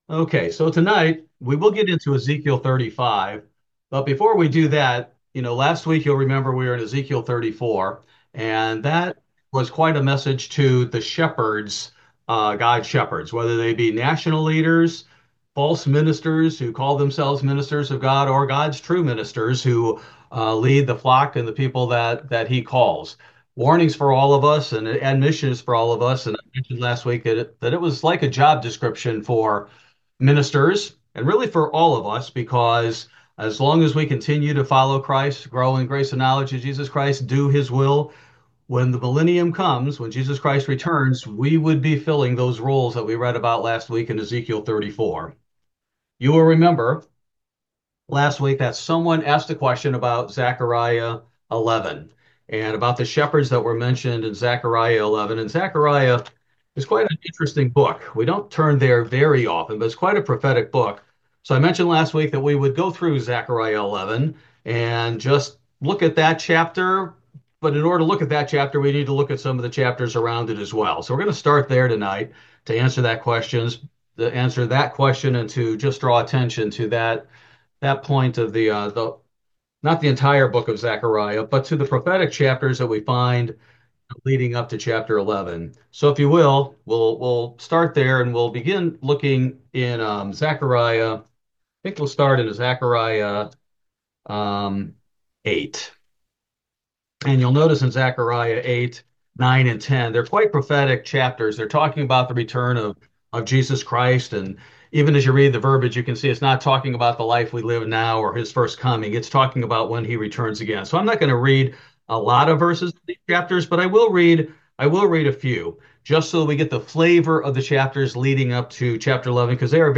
Bible Study: February 12, 2025